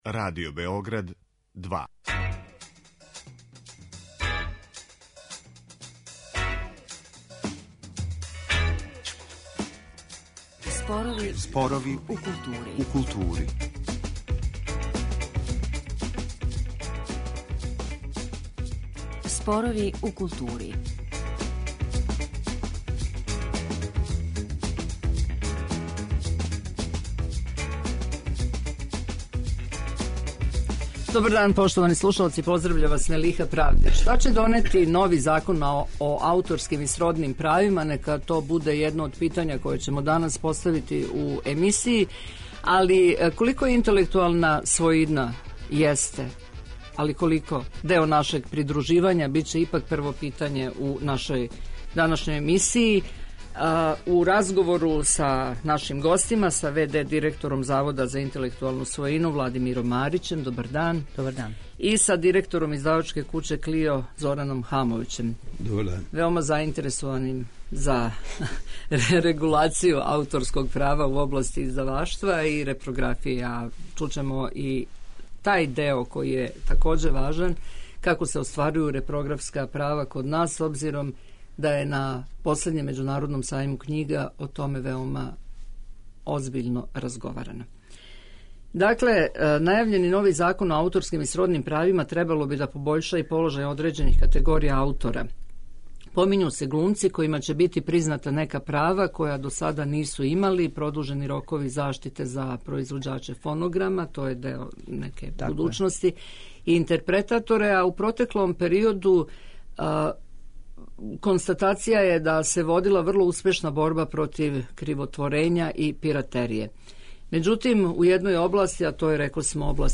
Ова и друга питања везана за ауторска права, али и за интелектуалну својину генерално, предмет су нашег разговора.